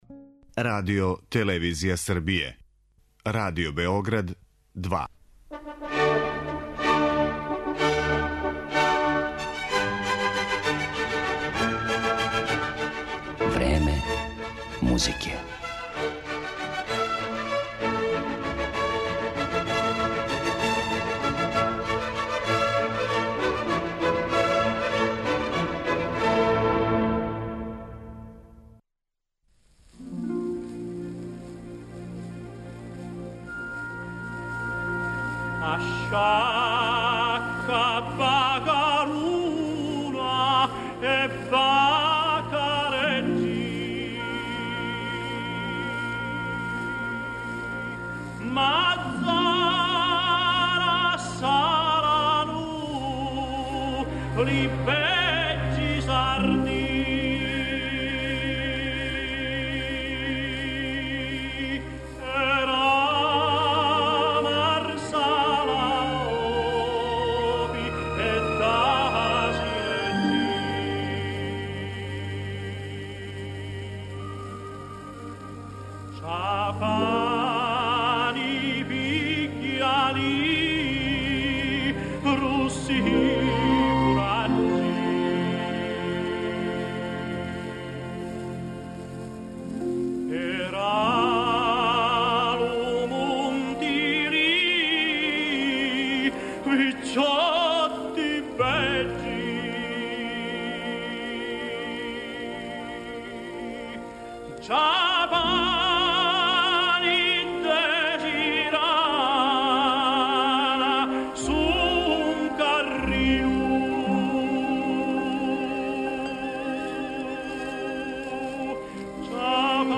слушаћете арије из најпознатијих опера Пучинија